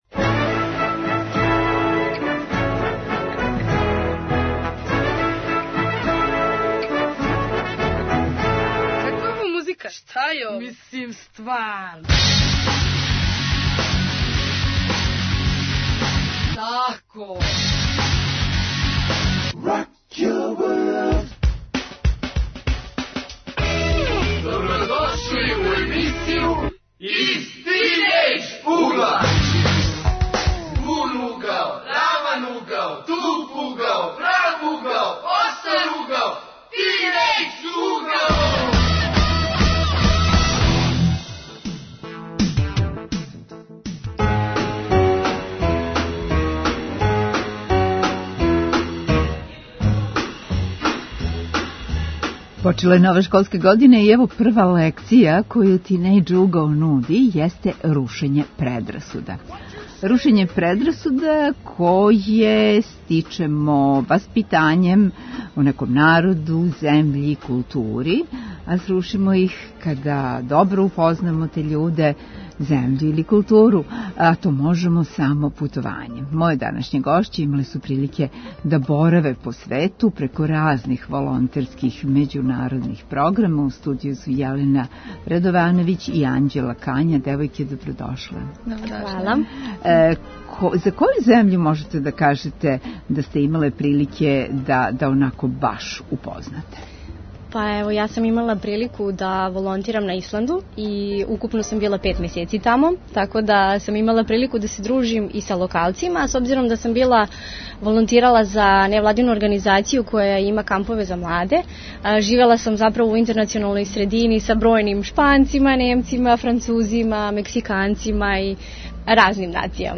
Гости су млади који су, преко разних програма, имали прилике да бораве по свету.